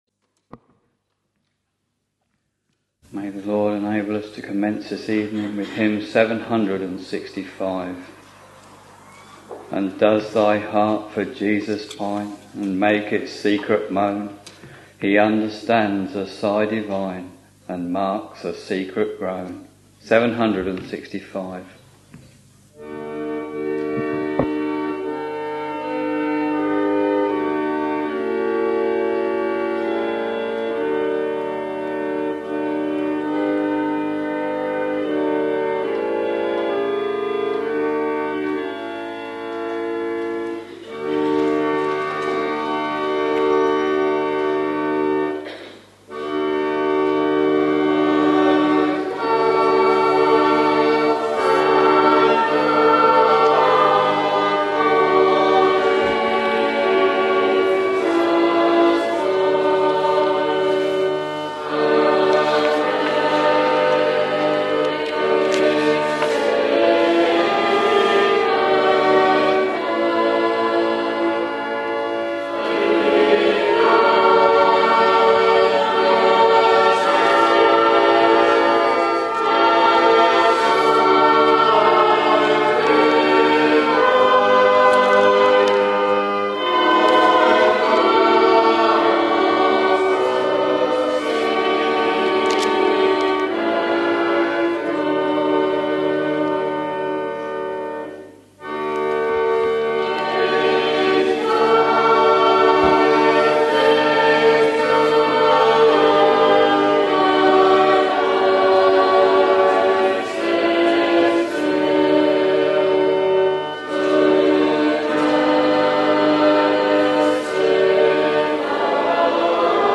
Sunday, 14th June 2015 — Evening Service Preacher
Hymns: 765, 798, 176 Reading: Matthew 15:21-28; 1 Samuel 1 Bible and hymn book details Listen Download File